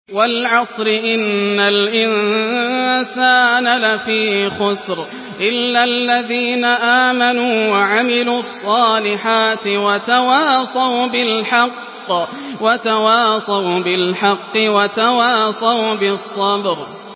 Surah العصر MP3 by ياسر الدوسري in حفص عن عاصم narration.
مرتل